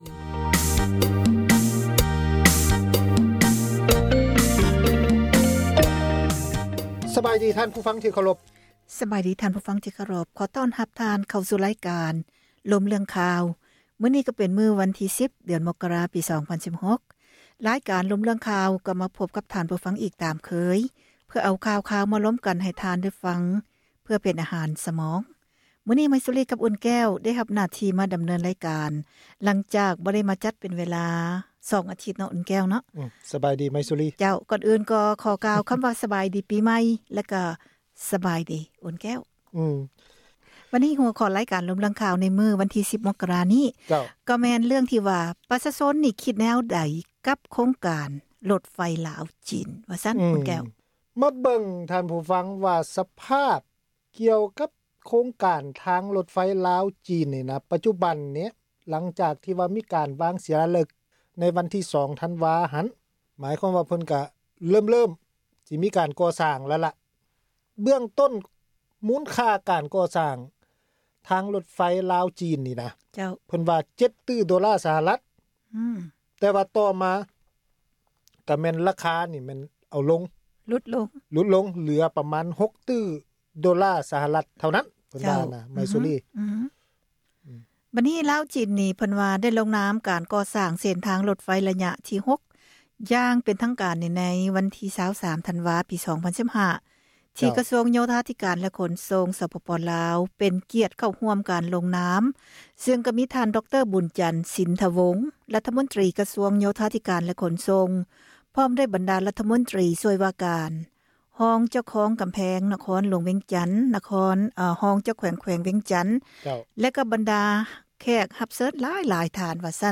ການສົນທະນາ ເຖິງເຫດການ ທີ່ເກີດຂຶ້ນ ໃນແຕ່ລະມື້ ທີ່ມີຜົນກະທົບ ຕໍ່ຊີວິດປະຈໍາວັນ ຂອງຊາວລາວ ທົ່ວປະເທດ ທີ່ ປະຊາສັງຄົມ ເຫັນວ່າ ຂາດຄວາມເປັນທັມ.